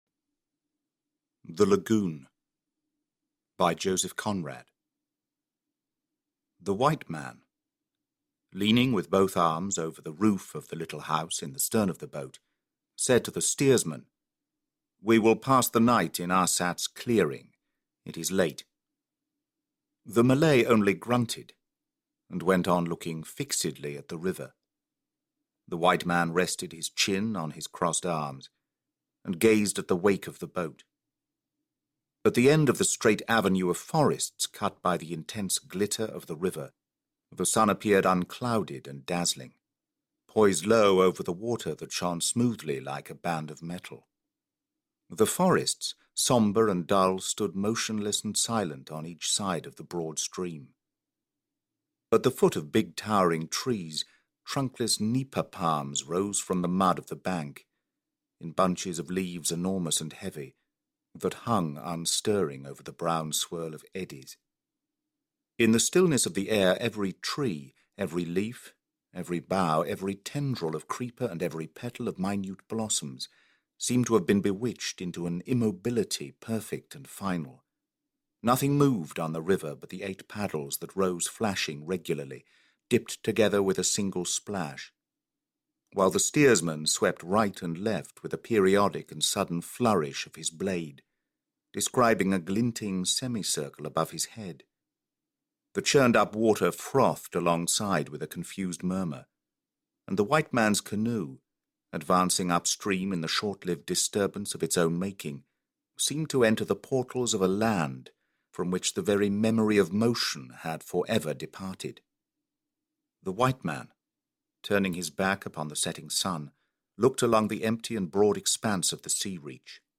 The Brown Hand: A Conan Doyle Thriller (Audiobook)